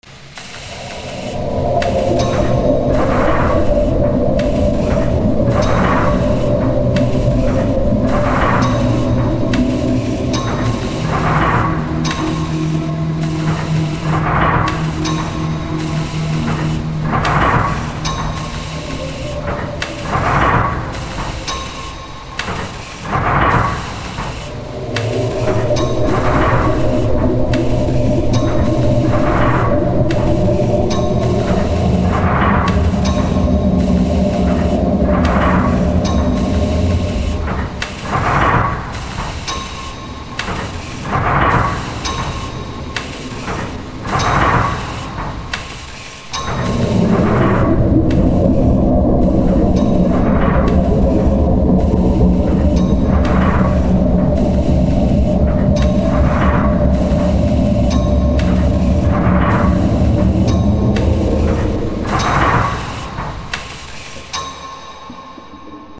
factory